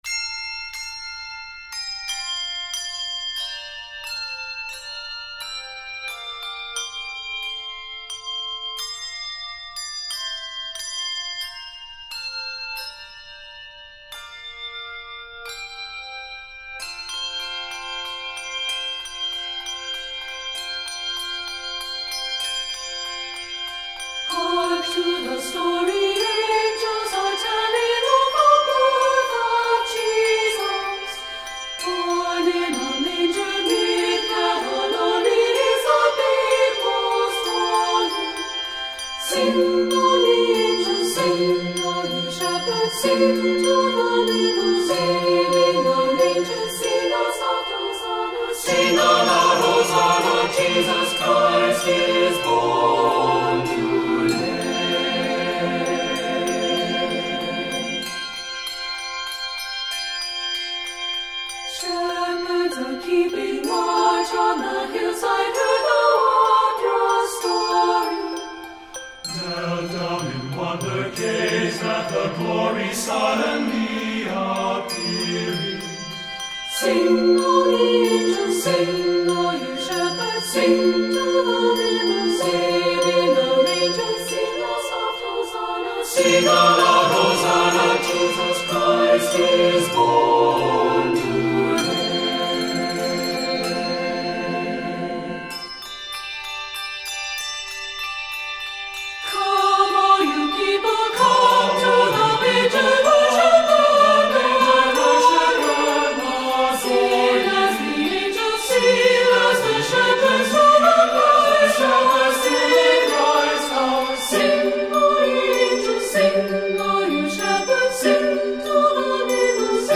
Composer: Polish Carol
Voicing: SATB